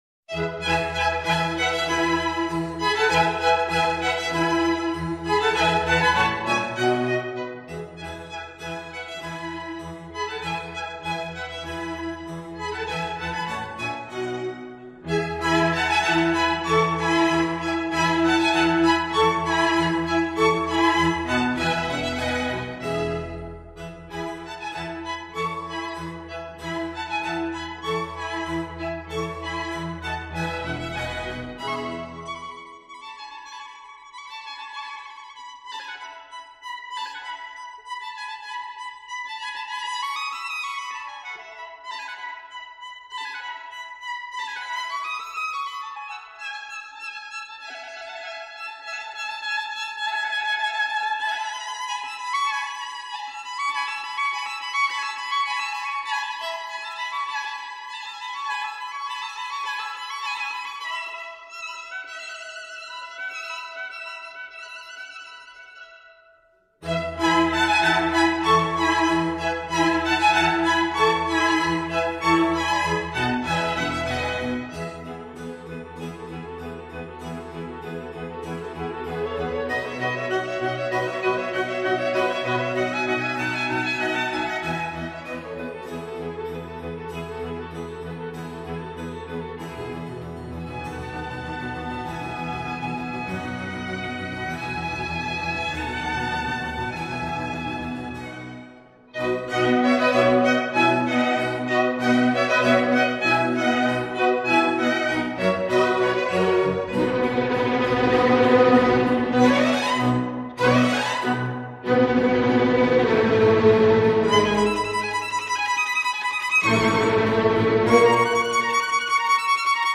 Laissez vous porter par ce morceau classique " le Printemps" de Vivaldi.( il n'est plus muet) 1) Ecoute simple du morceau 2) Ecoute active du morceau.